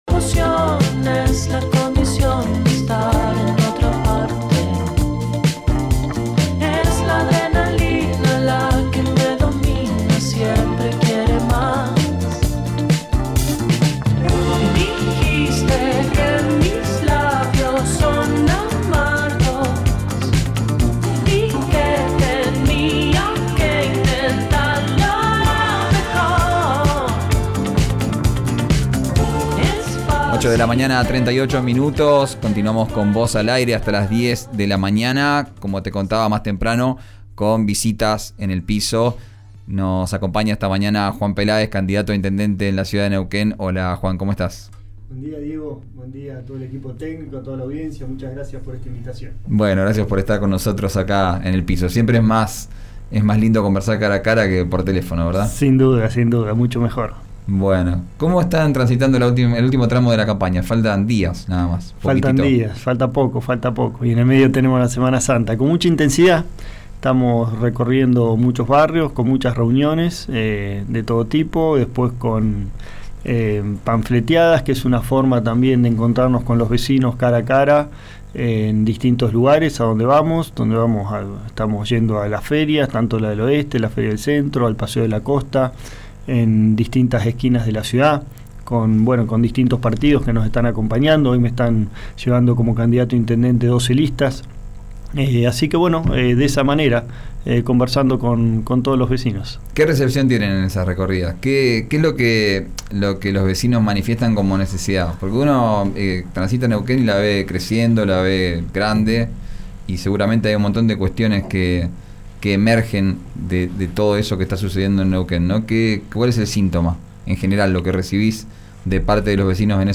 El candidato a Intendente de Neuquén por Comunidad, Juan Peláez, visitó el estudio de RÍO NEGRO RADIO. Escuchá la entrevista en 'Vos al aire'.